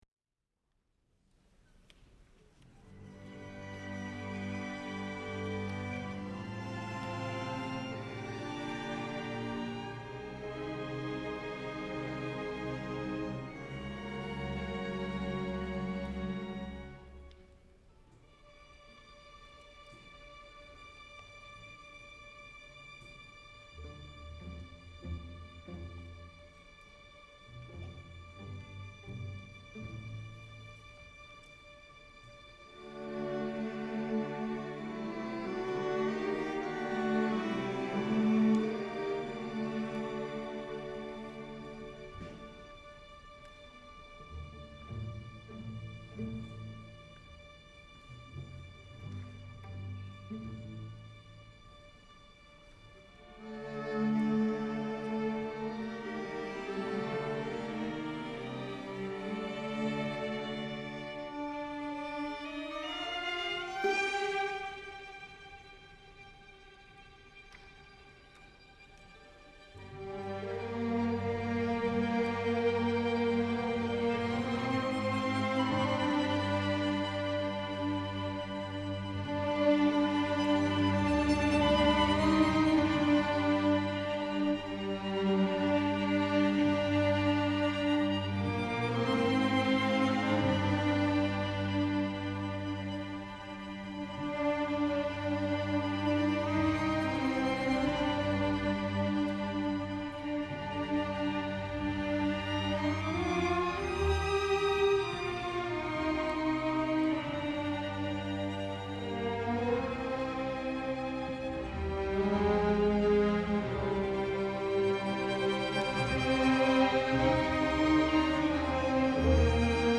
Santa Barbara City College Symphony Concert, Spring 2007